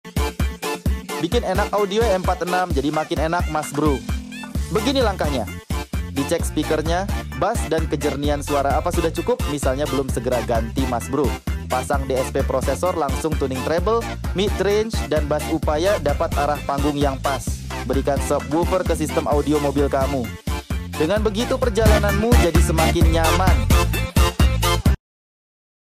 Sperti nonton konser secara langsung ya guys.